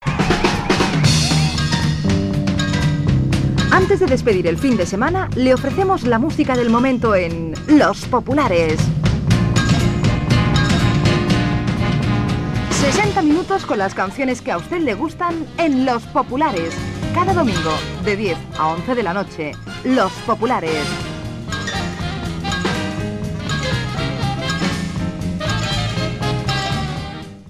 Promoció del programa